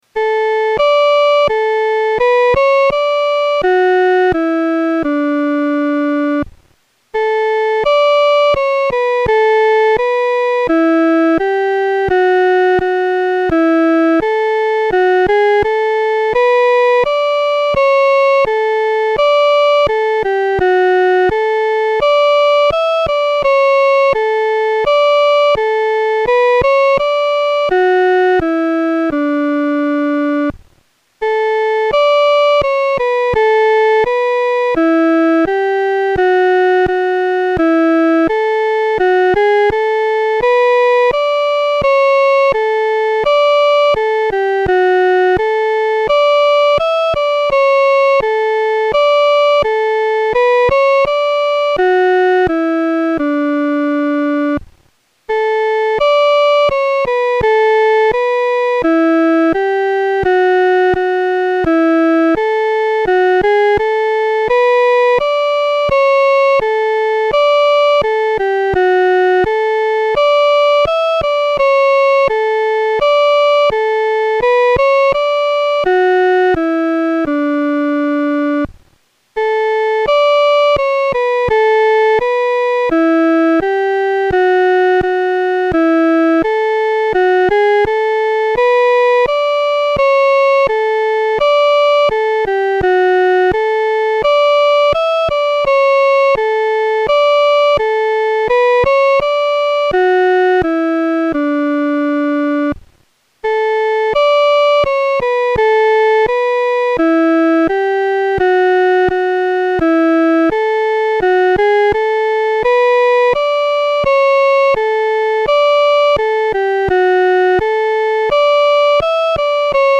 伴奏
这首诗的曲调活跃，和声变化色彩丰富；与前面五首赞美诗的和声处理方面很不同，是一首典型的“众赞歌”。